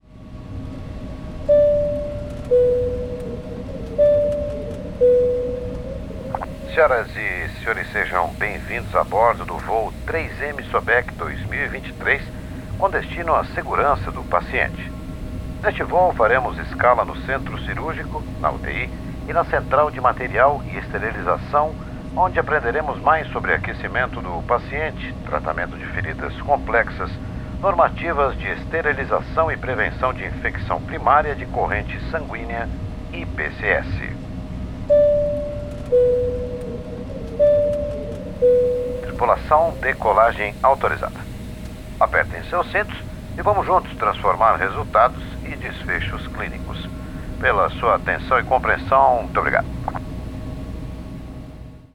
– Locução e Sound Design
Locução em português e ambientação sonora de aeronave